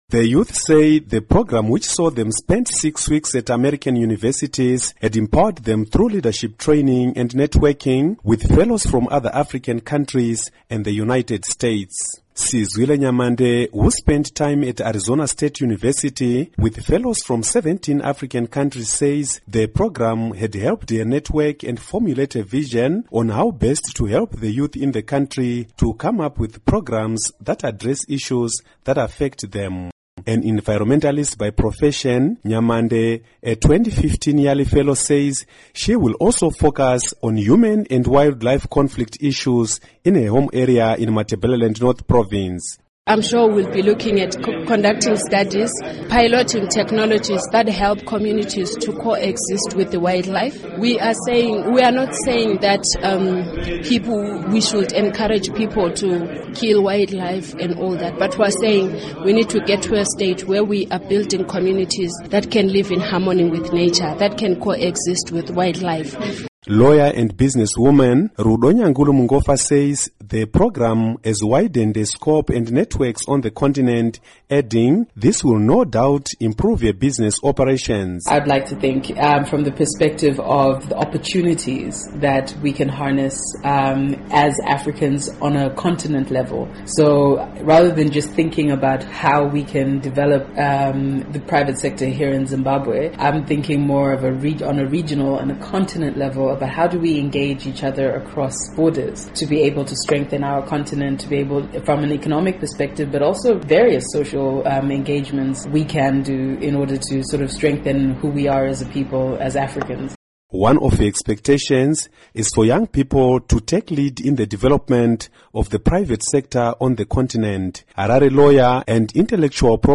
Report on Young African Leaders